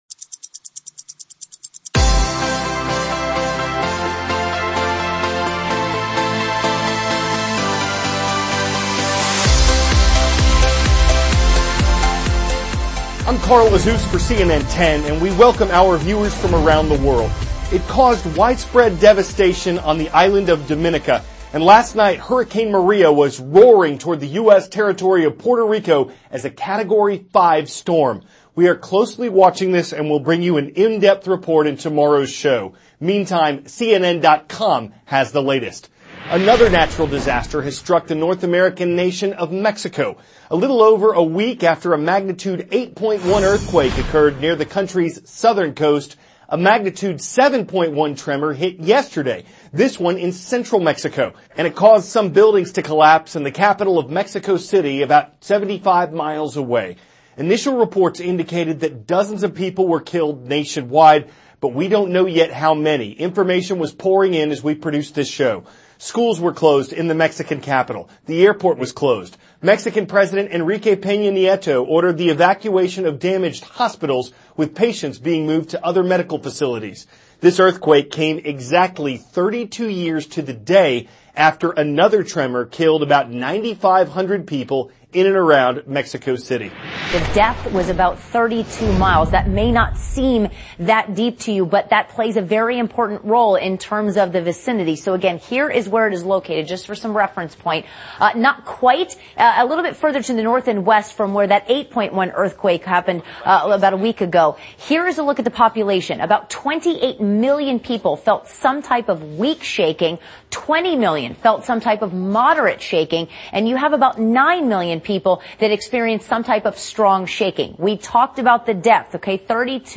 CARL AZUZ, cnn 10 ANCHOR: I`m Carl Azuz for cnn 10.